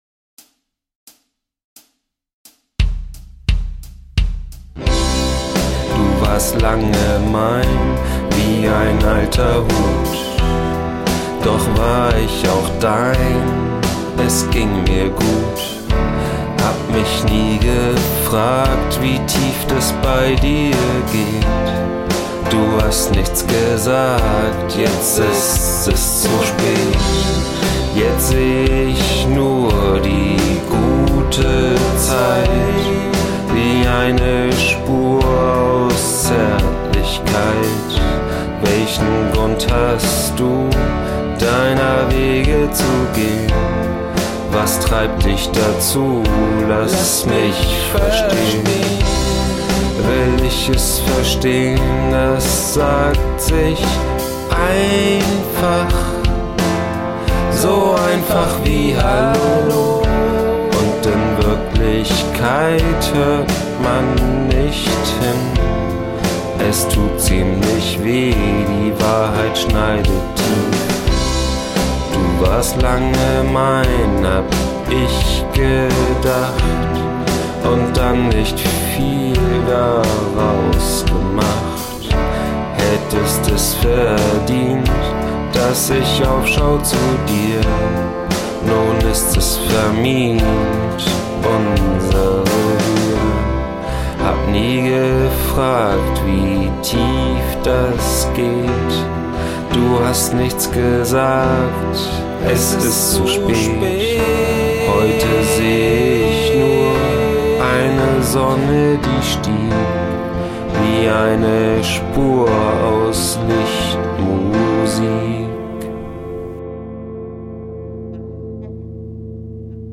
Heute Premiere mit echter Gitarre :D